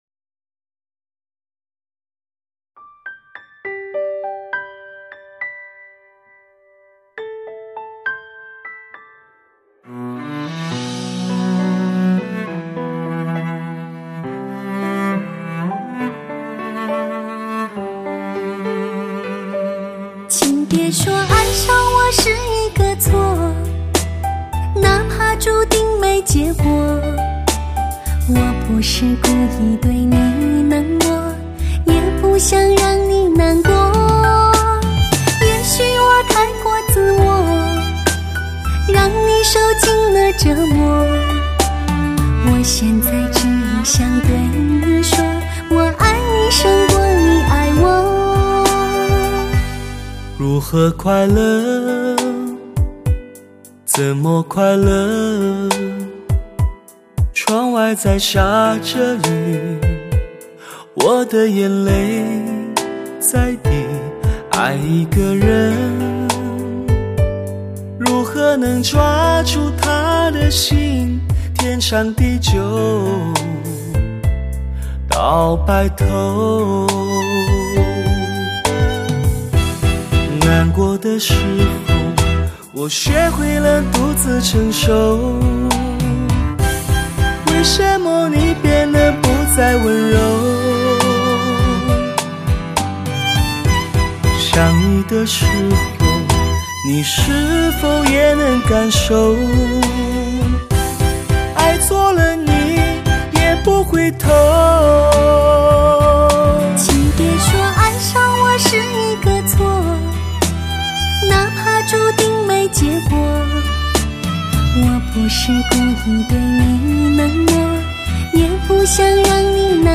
情歌一首首，只为你分享！